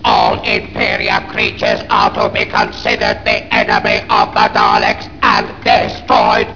dalek.wav